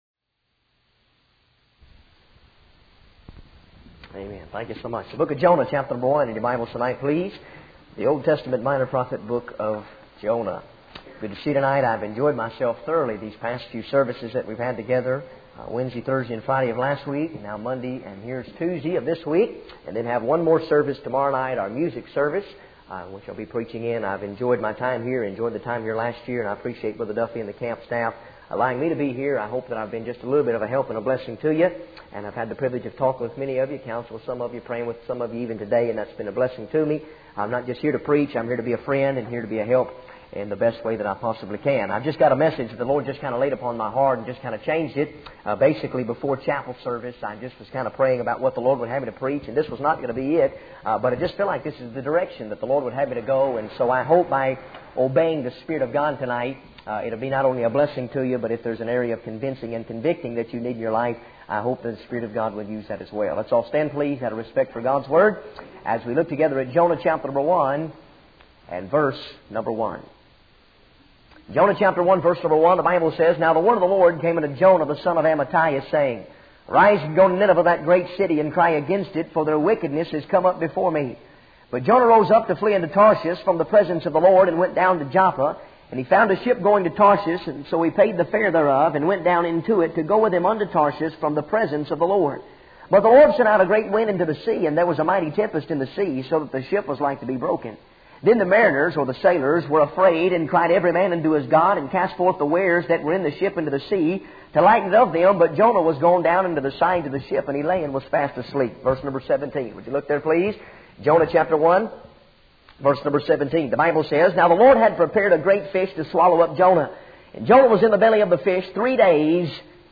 In this sermon, the preacher shares a personal story about a missionary fellow who was asked to give his testimony in church.